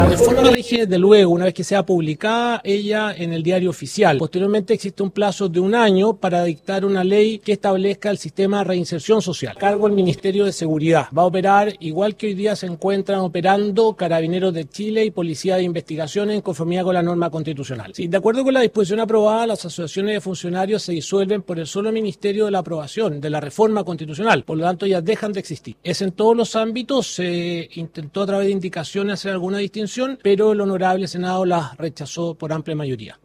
El ministro de Justicia y Derechos Humanos, Fernando Rabat, fue el primero en intervenir en la sesión, entregando los principales lineamientos de la reforma.